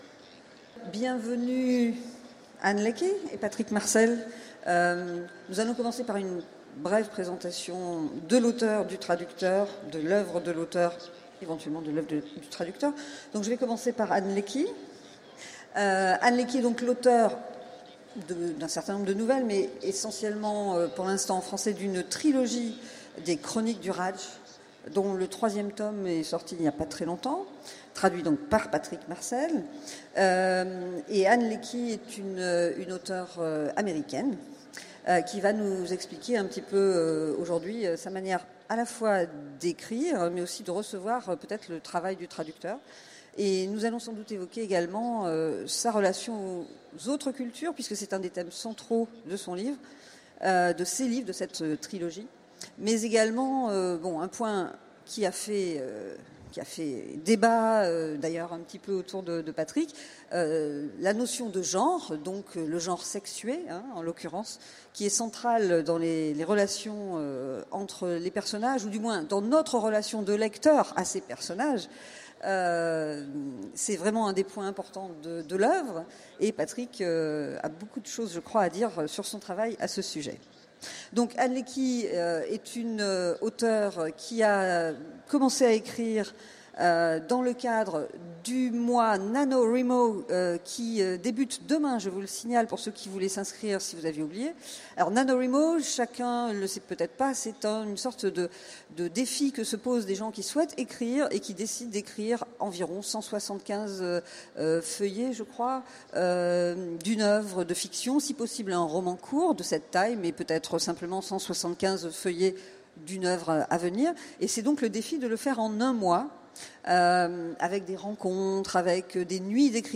Utopiales 2016 : Conférence L’auteur et son ombre